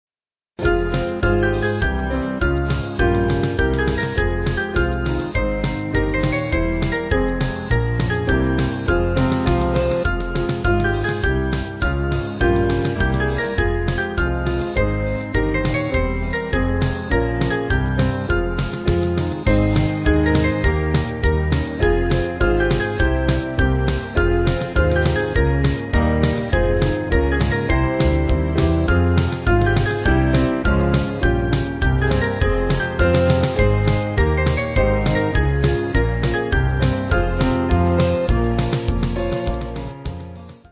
Small Band
Faster